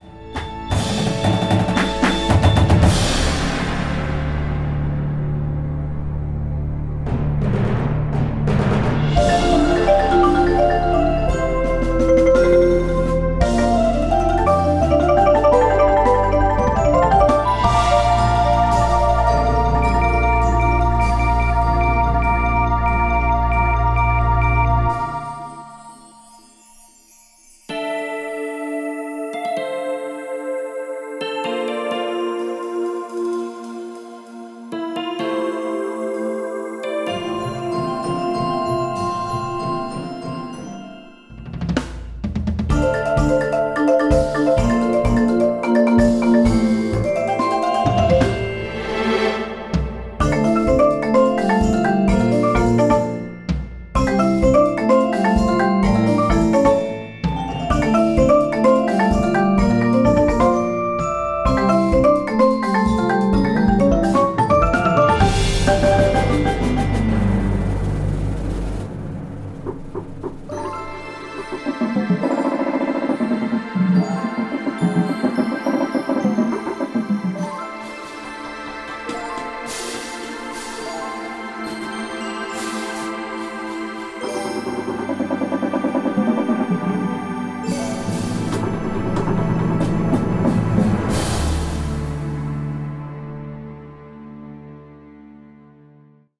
Indoor Percussion Shows
Front Ensemble